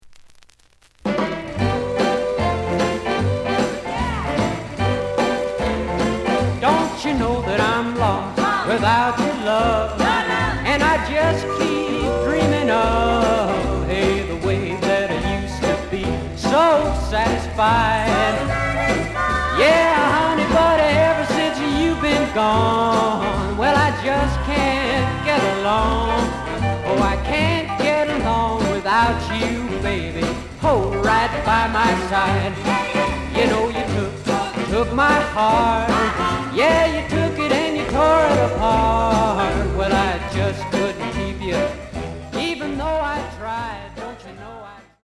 The listen sample is recorded from the actual item.
●Genre: Rhythm And Blues / Rock 'n' Roll